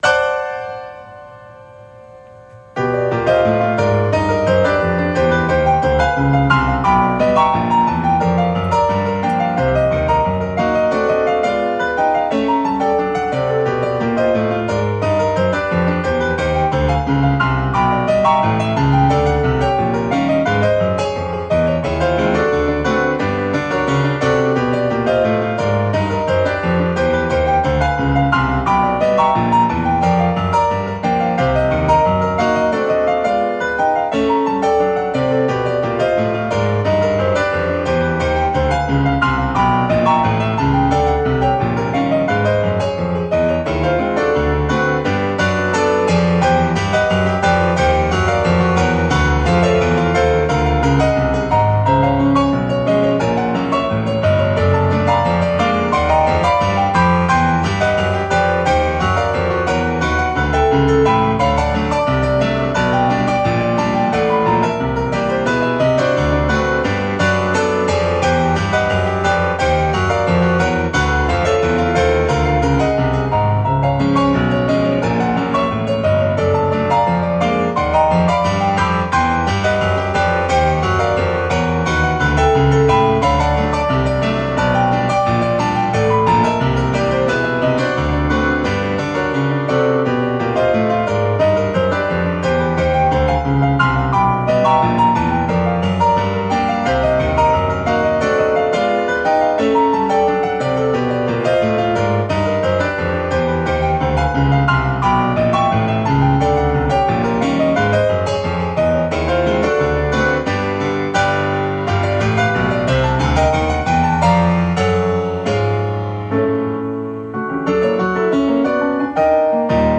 This year’s card is a theologically and harmonically twisty Christmas carol, with a text freely adapted from a carol in the 15th-century Stanhope Abbey manuscripts.